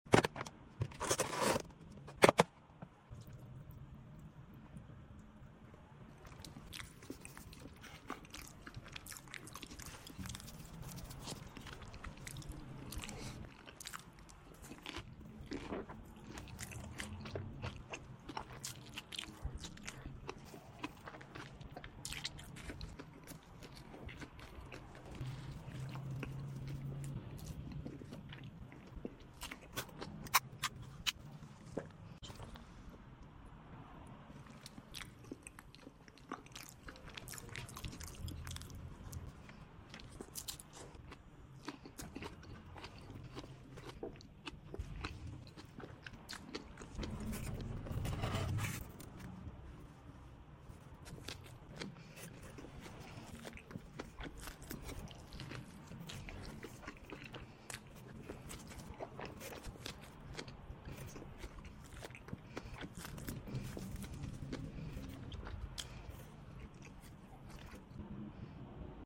Mukbang yummy burger and spaghetti sound effects free download